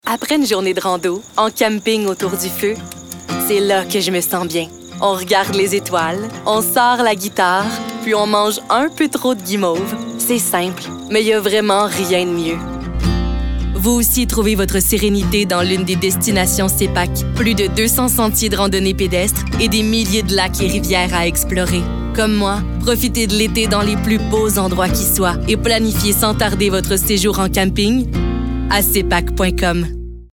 Démos voix